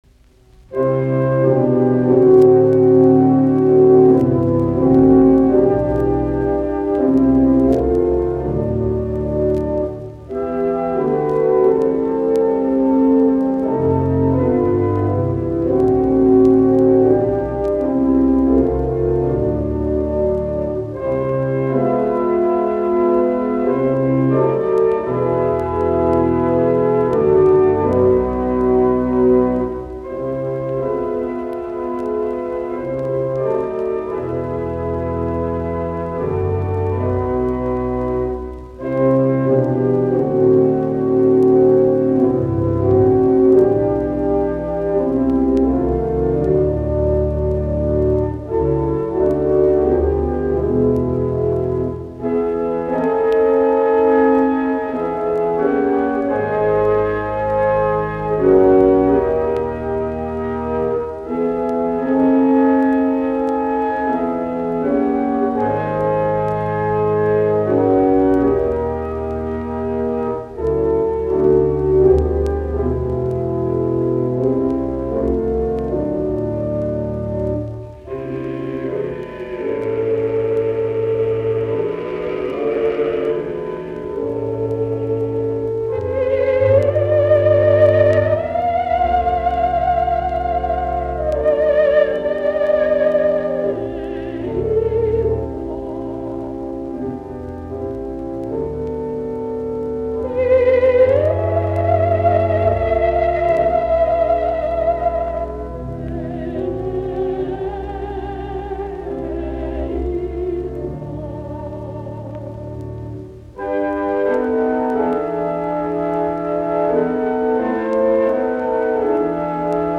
Soitinnus: Kuoro, urut.